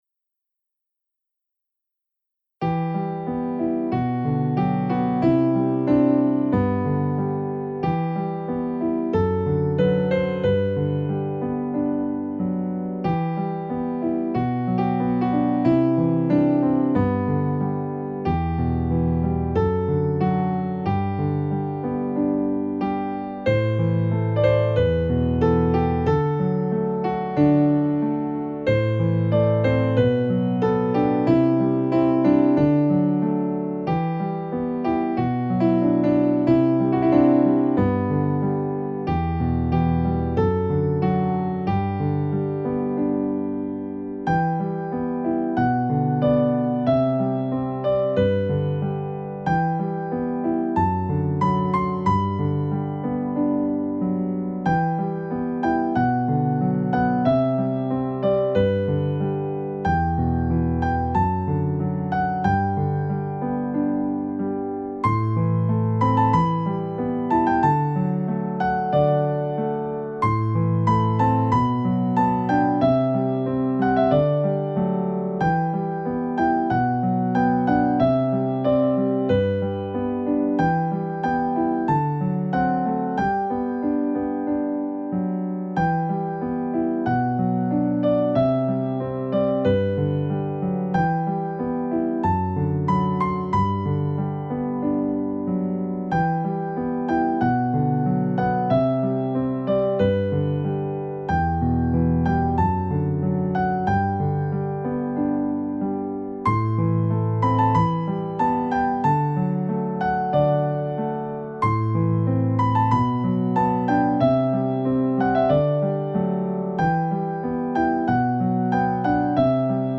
This beautiful Celtic melody is easy to play.